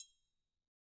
Triangle3-HitFM_v1_rr2_Sum.wav